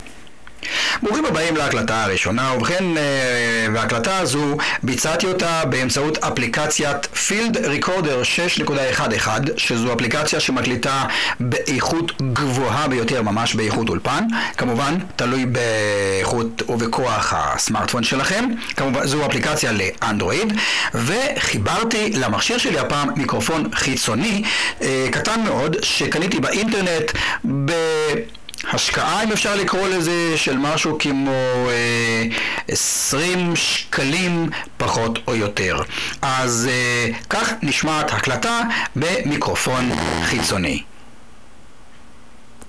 הקלטה בסמארטפון עם מיקרופון גרוע
הקלטת ניסיון לבדיקת הקלטה בסמארטפון, אלא שהתברר שהמיקרופון שבדקתי גרוע...